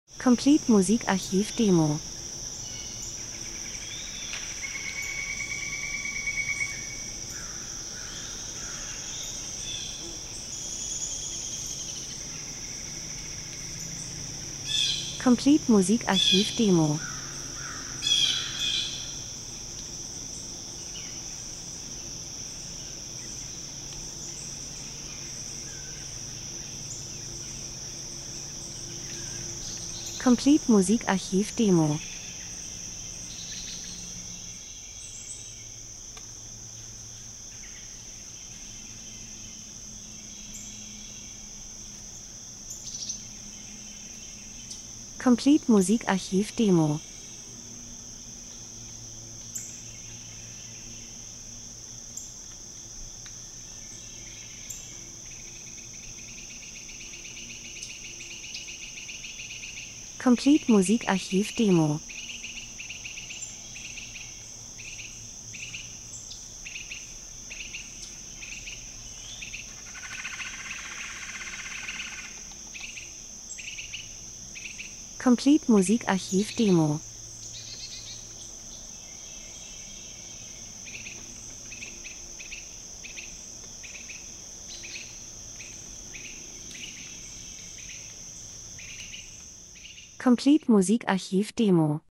Herbst -Geräusche Soundeffekt Vögel, Wiesen Wälder 01:30